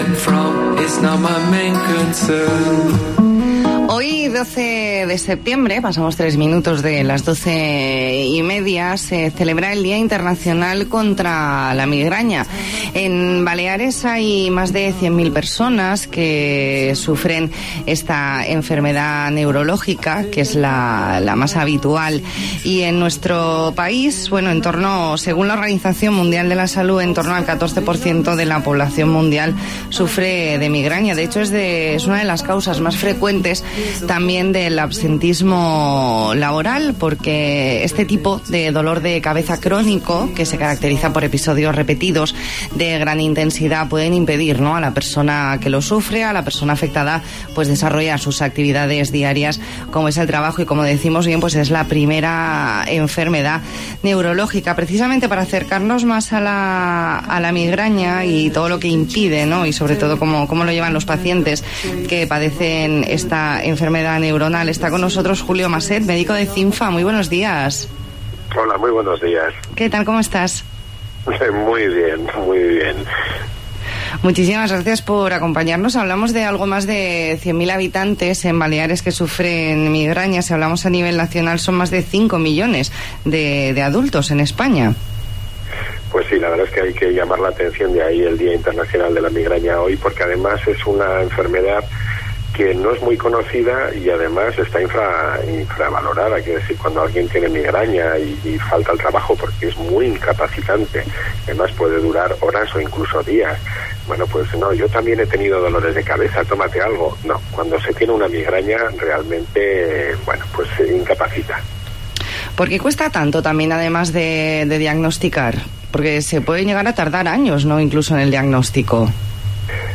Entrevista en La Mañana en COPE Más Mallorca, jueves 12 de septiembre de 2019.